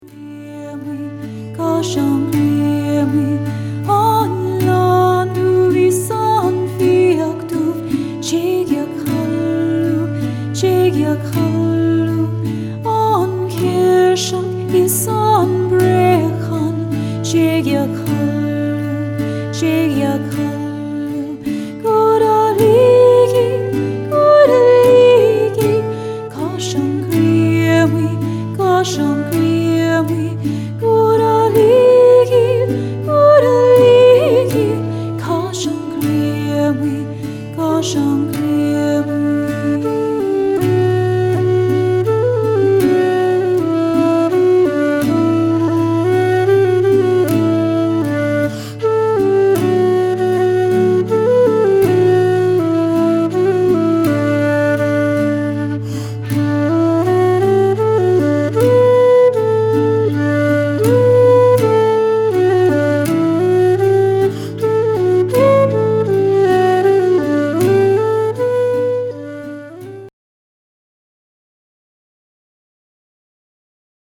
Austin Irish Singer 1
Backed by energetic rhythms, she shares her rich soaring voice in Irish Gaelic and in English.
Austin-Irish-Singer-1-Einini.mp3